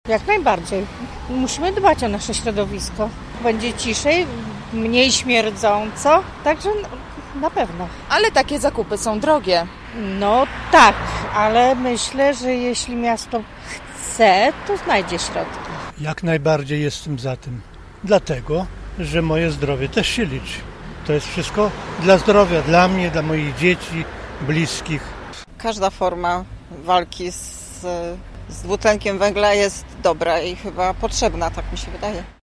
My także zapytaliśmy gorzowian, czy miasto powinno inwestować w autobusy zeroemisyjne: